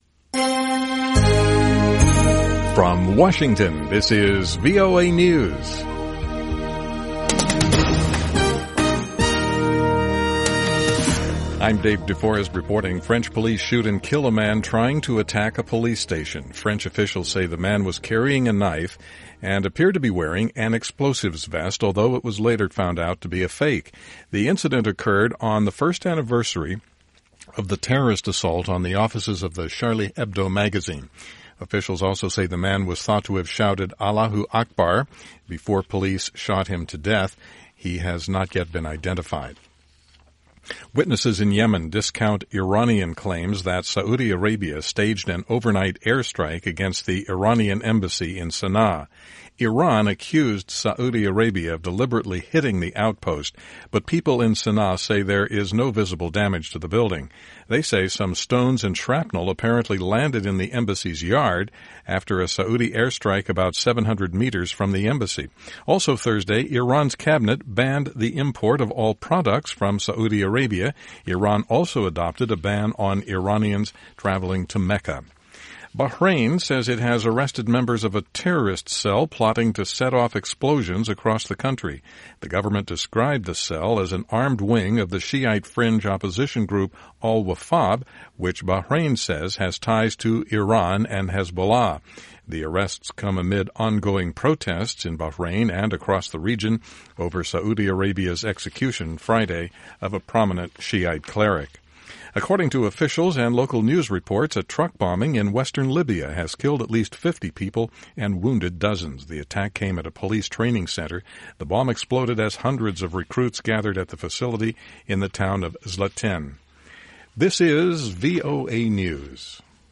VOA English Newscast: 2100 UTC January 7, 2016